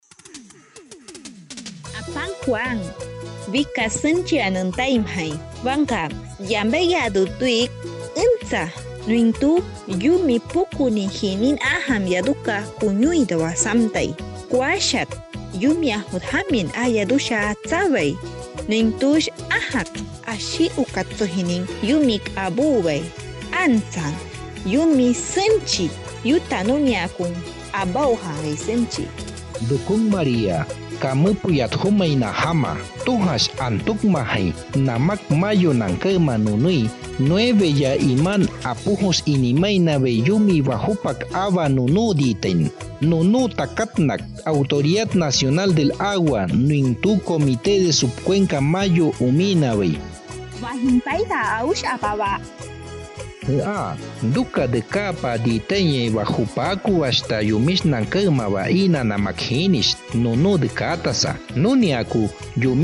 SPOT RADIAL: IMPORTANCIA DE LAS ESTACIONES HIDROLÓGICAS AUTOMÁTICAS - IDIOMA AWAJUN | Mayo